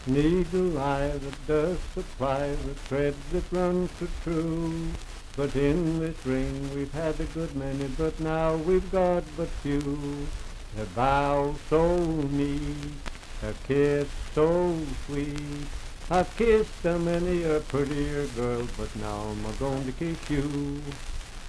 Unaccompanied vocal music
Dance, Game, and Party Songs
Voice (sung)
Franklin (Pendleton County, W. Va.), Pendleton County (W. Va.)